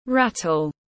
Cái lúc lắc tiếng anh gọi là rattle, phiên âm tiếng anh đọc là /ˈræt.əl/
Rattle /ˈræt.əl/